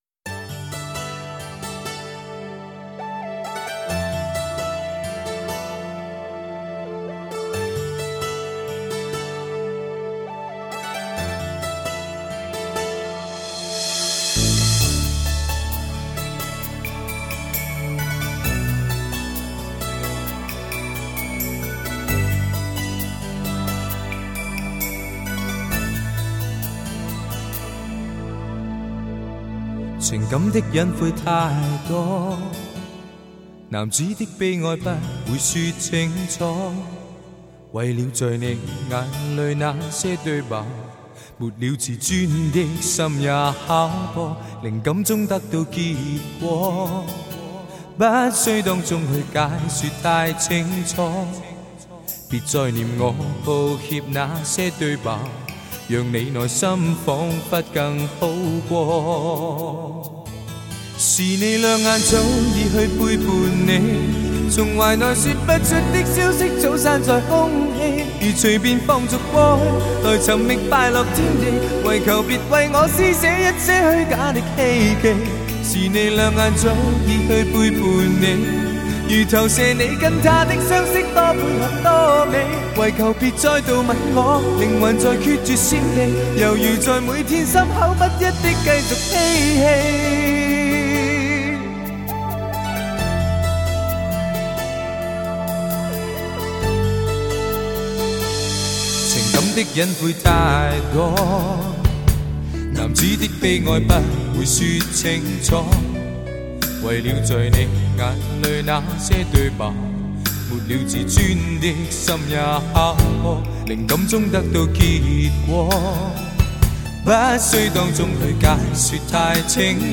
不俗的唱功征服了无数的歌迷。